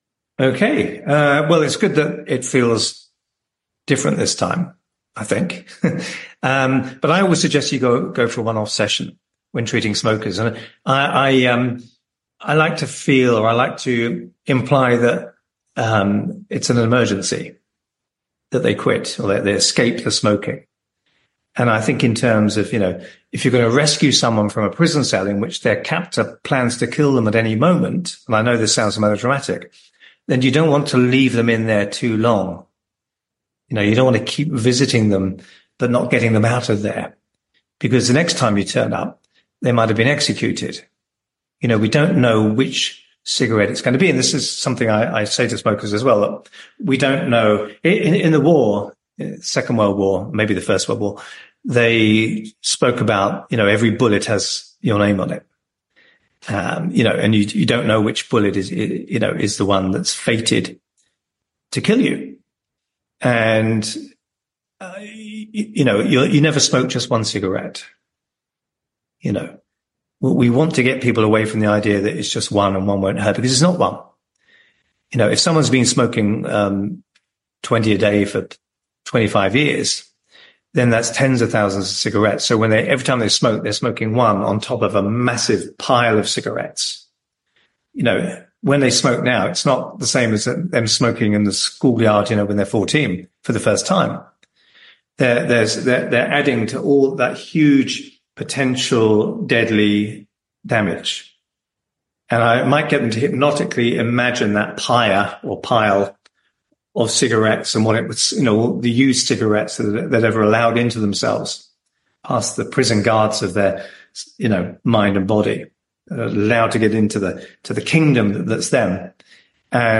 If you’d like to delve into this topic a little further, I’d direct you to the recording of my answer to a question I was asked on a recent Q&A call: